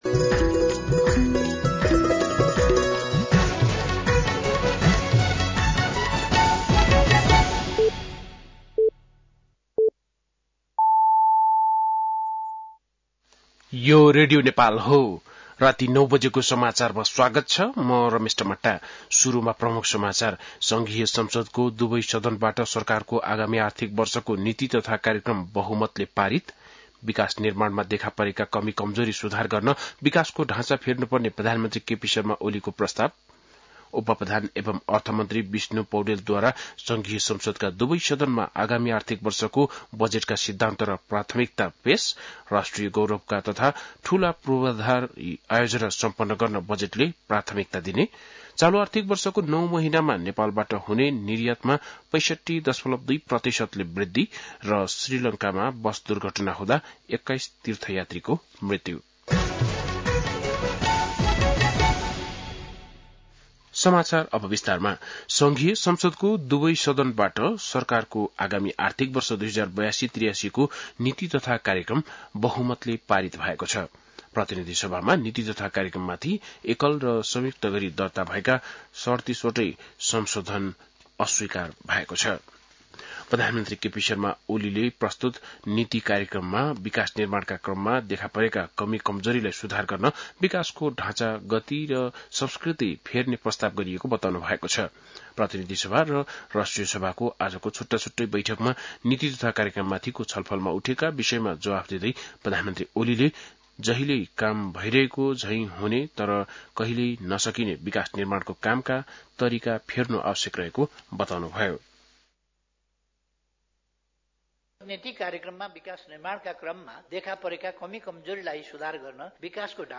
बेलुकी ९ बजेको नेपाली समाचार : २८ वैशाख , २०८२
9-PM-Nepali-NEWS-1-1.mp3